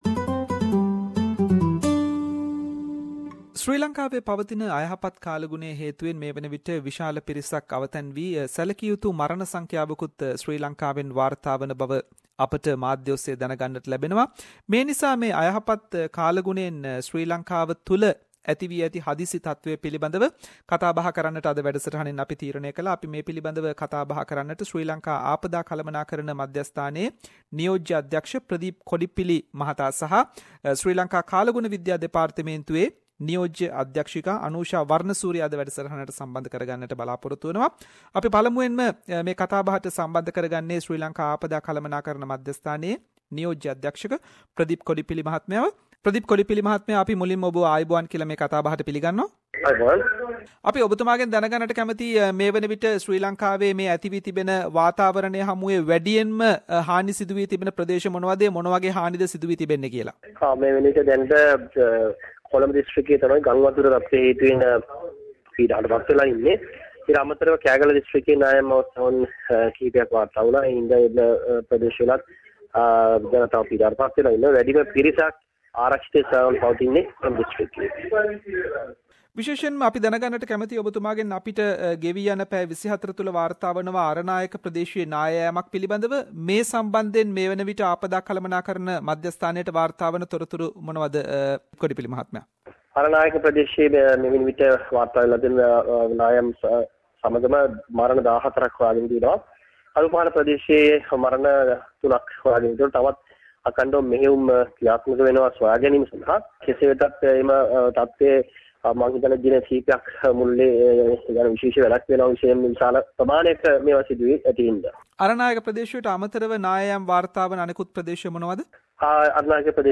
special interviews